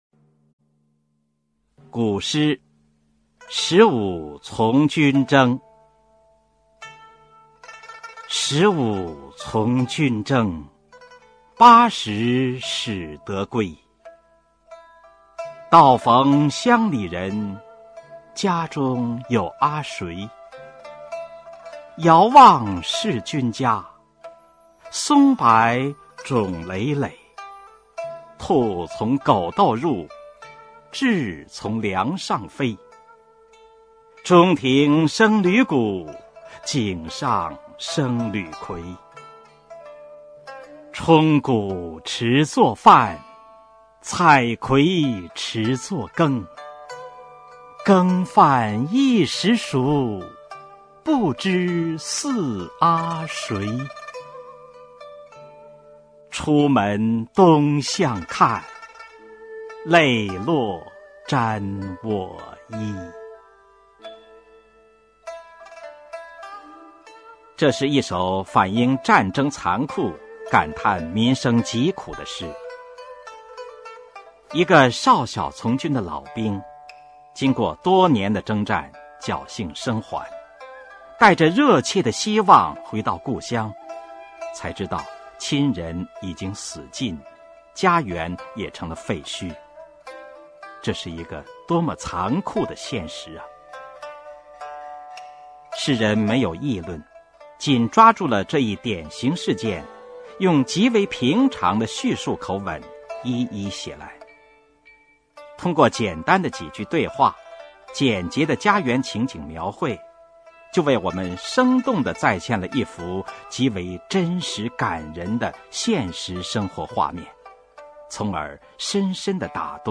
《十五从军征》原文和译文（含赏析、朗读）　/ 佚名